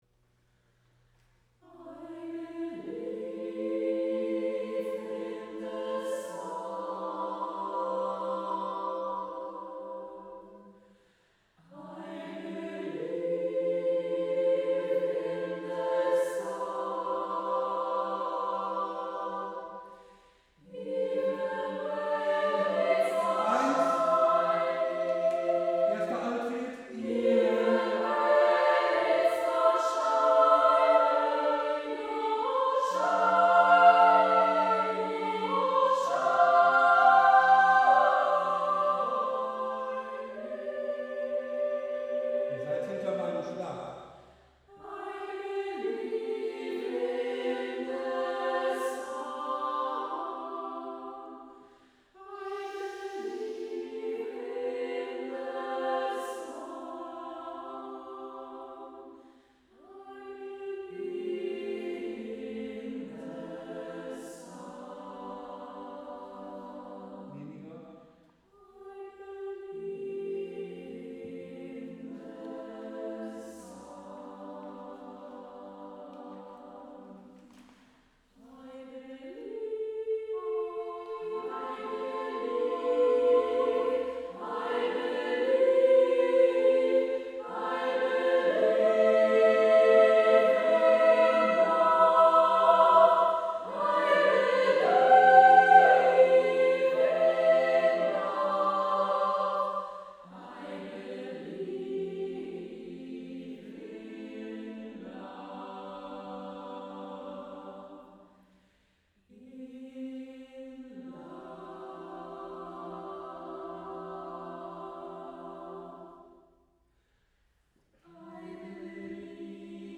Probenwochenende 4 | 06./07. März 2026 - Konzertchor Sång